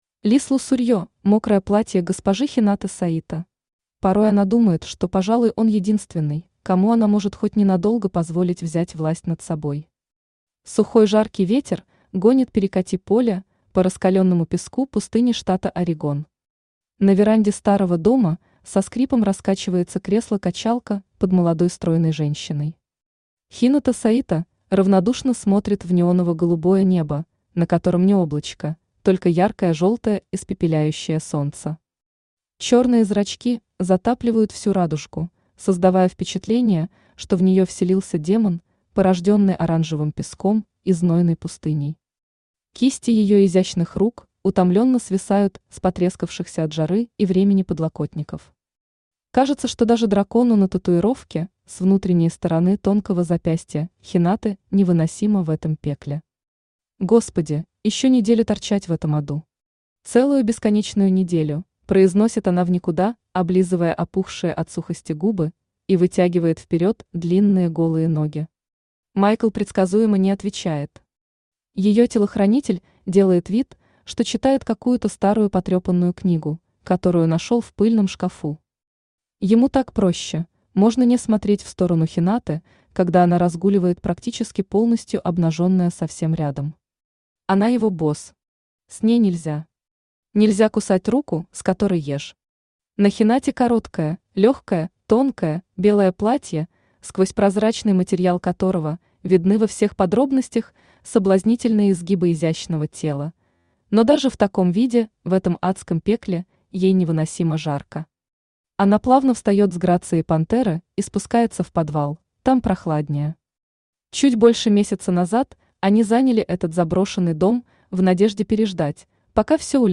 Аудиокнига Мокрое платье госпожи Хинаты Саито | Библиотека аудиокниг
Aудиокнига Мокрое платье госпожи Хинаты Саито Автор Лиз Лусурье Читает аудиокнигу Авточтец ЛитРес.